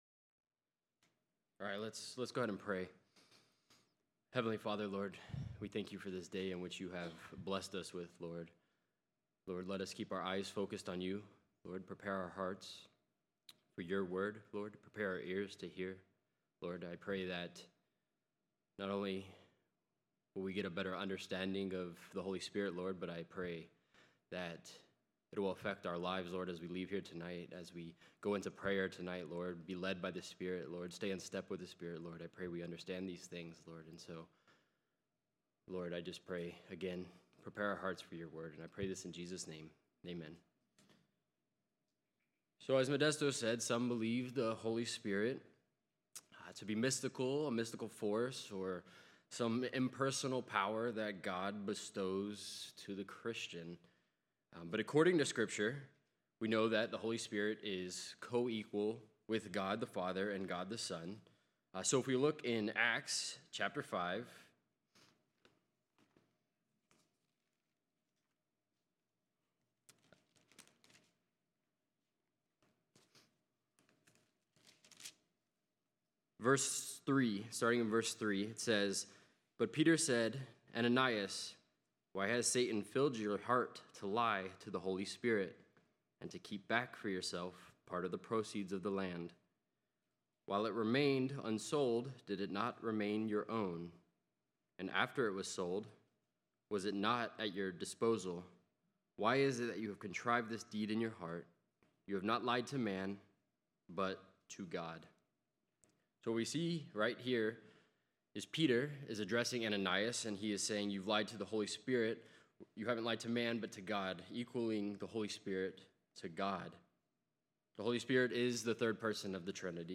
Service: Special Event Topics: The Holy Spirit